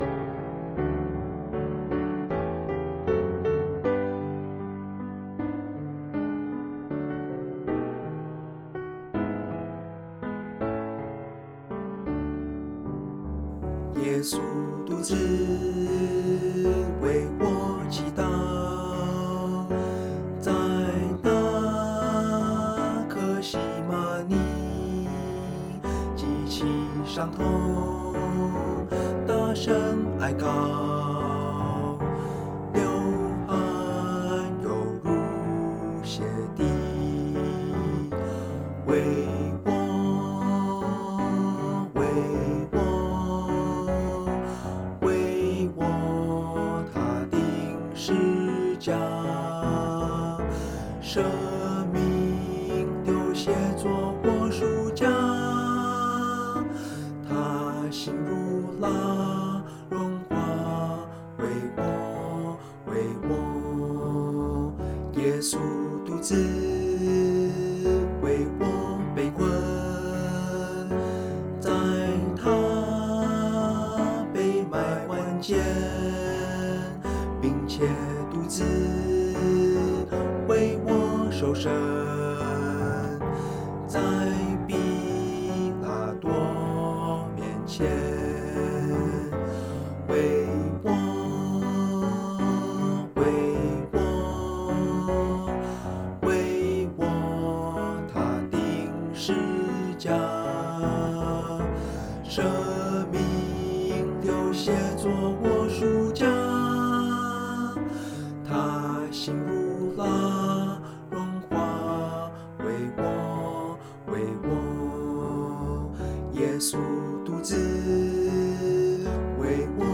F Major